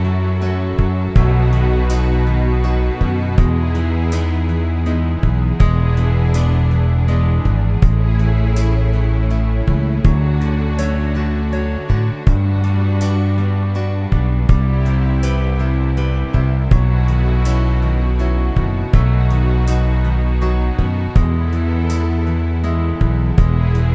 Minus Lead Guitar For Guitarists 2:54 Buy £1.50